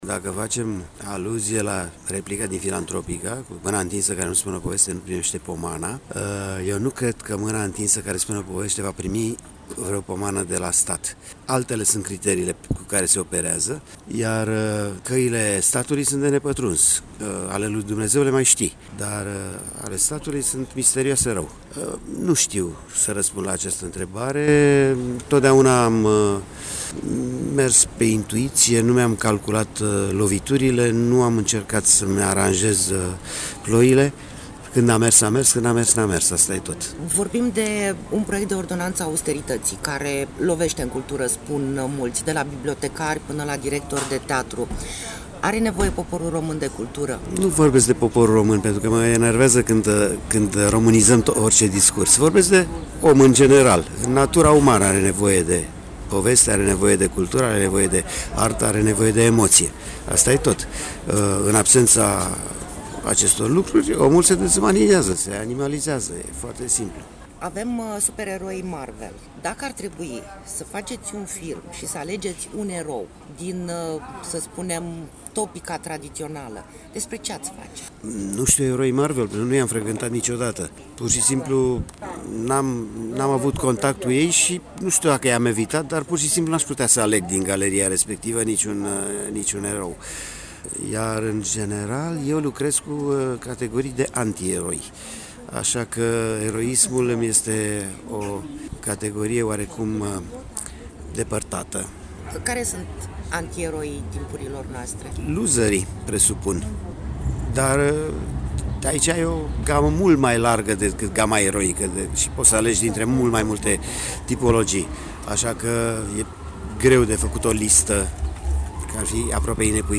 Interviu-Nae-Caranfil.mp3